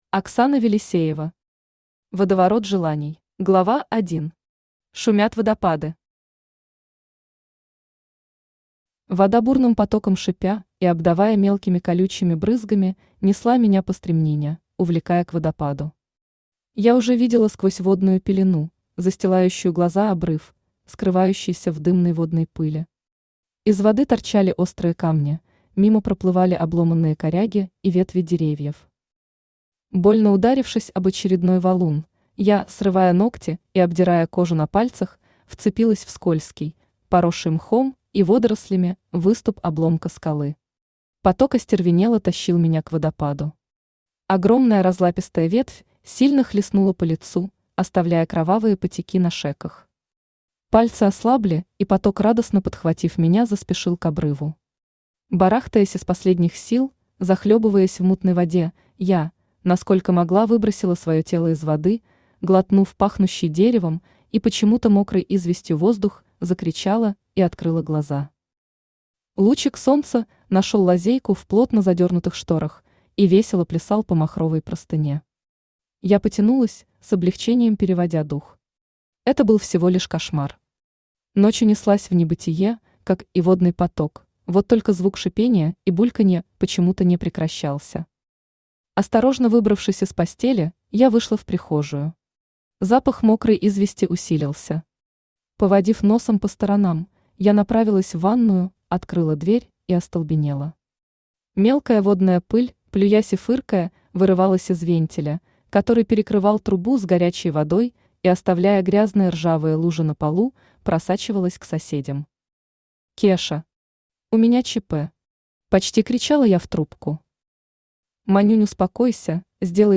Аудиокнига Водоворот желаний | Библиотека аудиокниг
Читает аудиокнигу Авточтец ЛитРес.